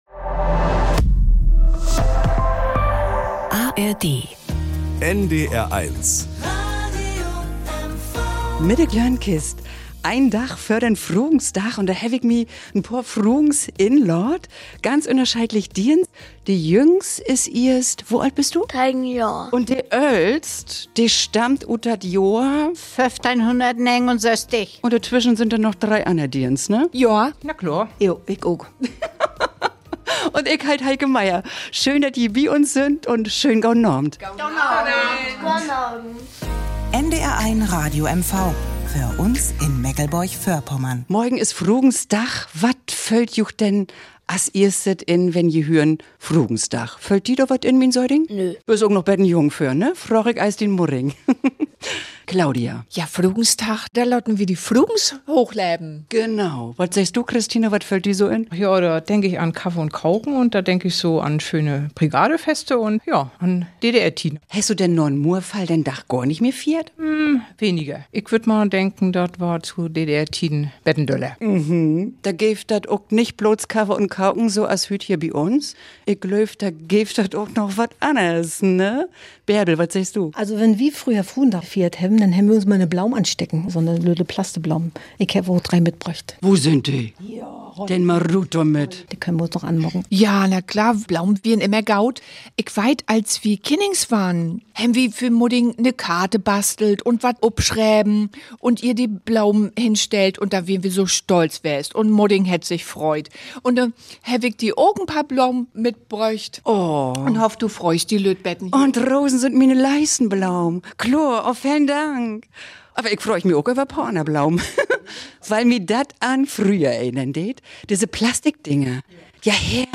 Jahrhunderts bis hin zu den Ostseewellen in Lied und am Strand: Wenn diese sechs Frauen zusammen kommen, treffen sechs Lebenswelten aufeinander und jede trägt etwas bei zur munteren Plauderei vor dem Mikrofon. Die Frauentags-Klönkist - mit Kaffee, Keksen und Saft und sogar mit Gesang zur Gitarre!